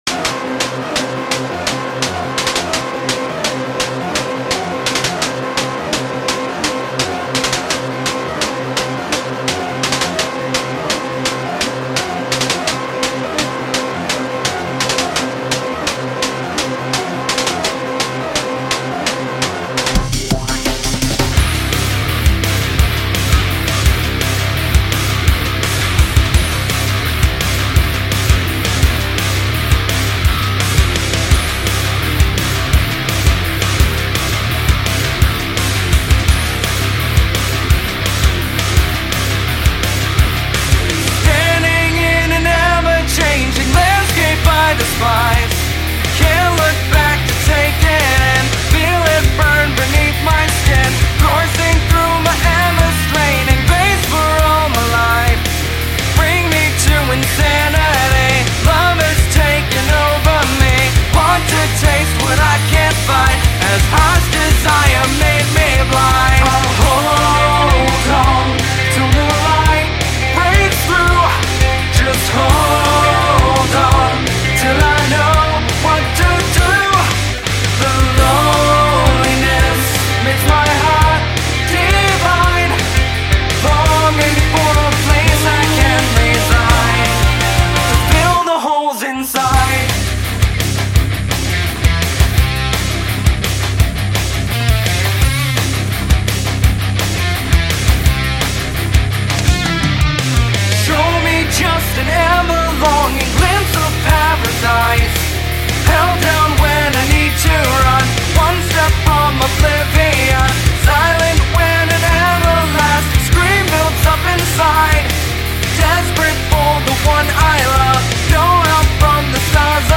Guitar, Bass, Vocals, Programming